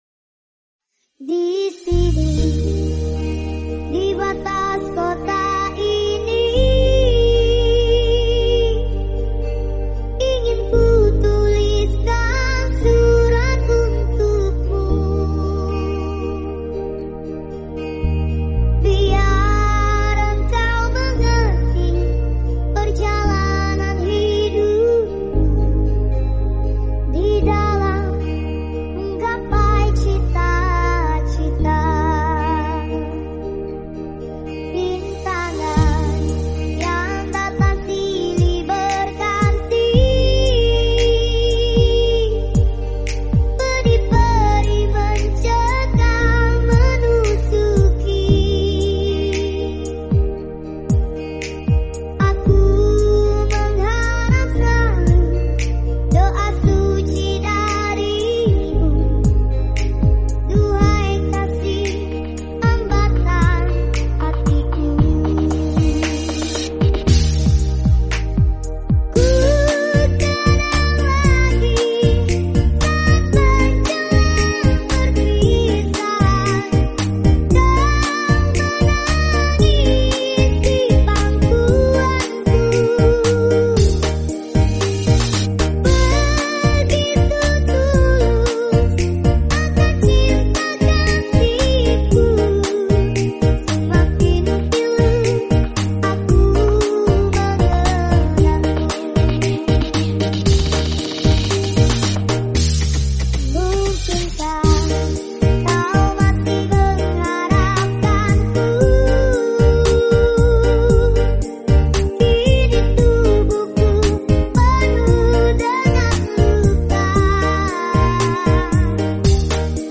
SLOW REMIX